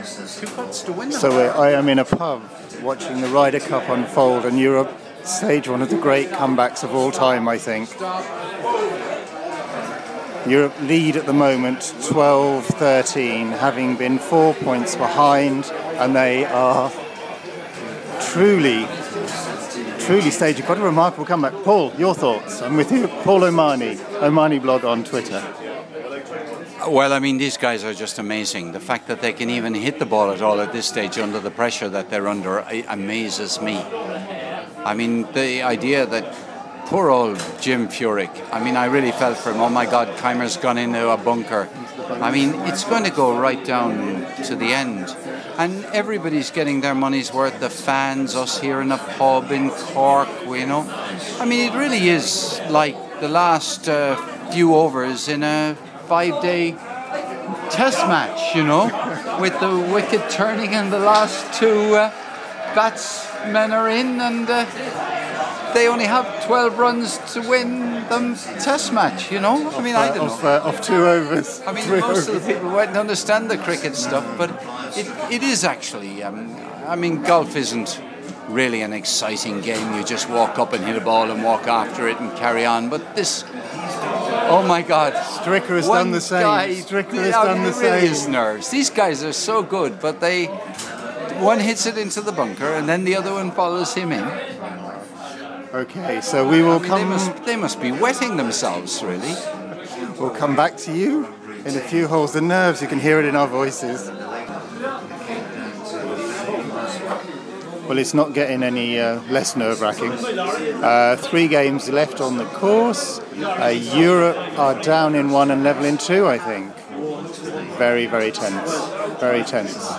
Ryder Cuo from a pub in Ireland